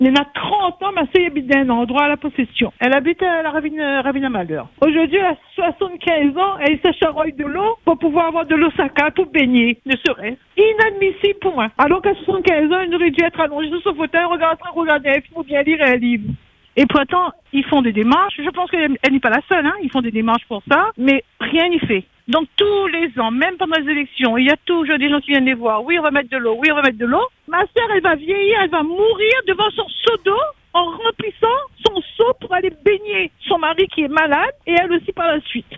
Un témoignage poignant. Une auditrice nous parle de sa sœur, âgée de 75 ans et vivant à La Possession. Privée d’eau courante chez elle, elle est obligée de se rendre à la Ravine à Malheur pour en récupérer.
Sa sœur raconte le cœur brisé cette situation inacceptable pour une personne de son âge, et lance un appel à l’aide.